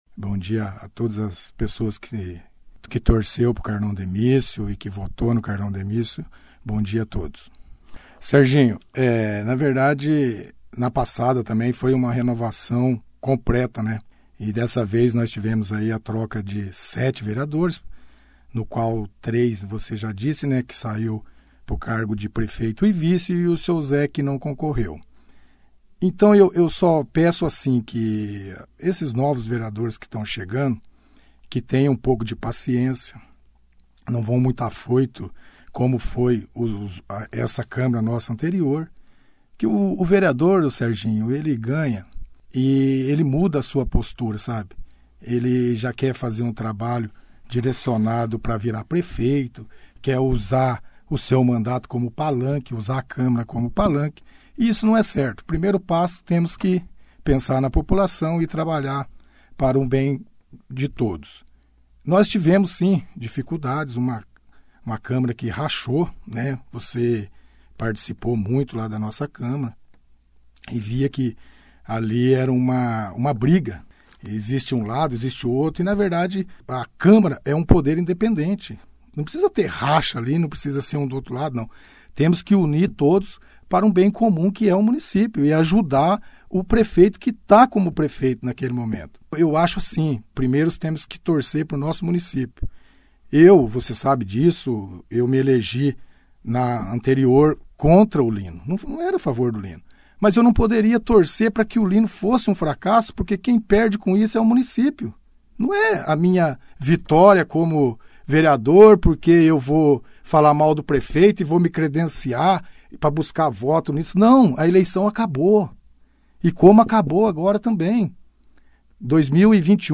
O vereador Antônio Carlos Demicio, (Carlão), foi reeleito no último dia 15 de novembro pelo PP com 412 votos, participou da 1ª edição do jornal Operação Cidade desta sexta-feira, 20/11, agradecendo os votos recebidos e falou do trabalho que pretende realizar no legislativo bandeirantense juntos aos novatos que estarão pela primeira vez assumindo um mandato.